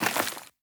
added stepping sounds
Glass_Grit_Mono_02.wav